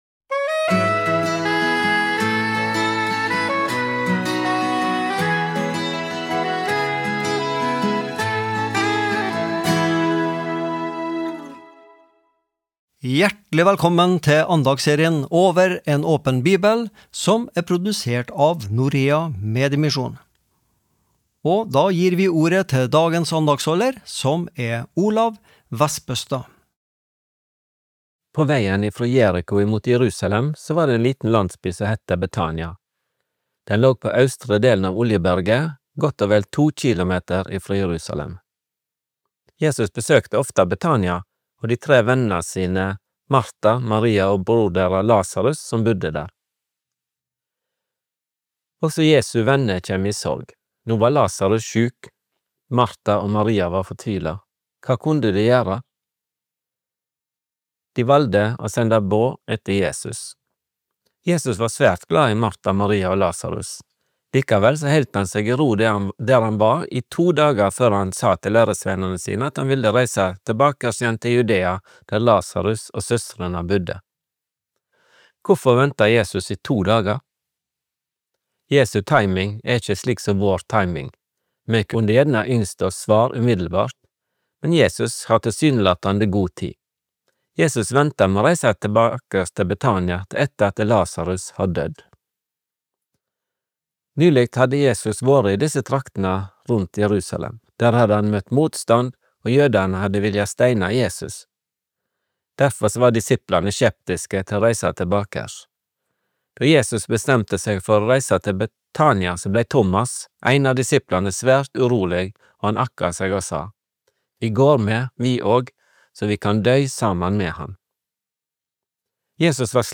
Daglige andakter (mand.-fred.). Forskjellige andaktsholdere har en uke hver, der tema kan variere.